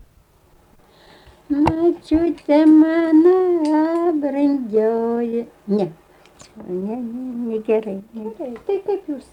rauda